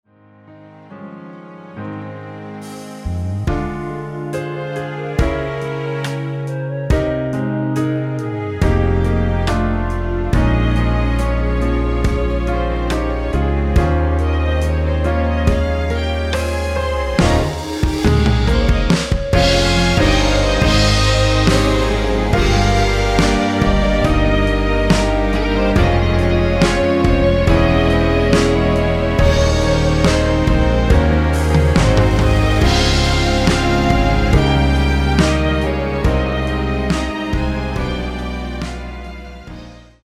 원키에서(-2)내린 멜로디 포함된(1절앞 + 후렴)으로 진행되는 MR입니다.
Ab
앞부분30초, 뒷부분30초씩 편집해서 올려 드리고 있습니다.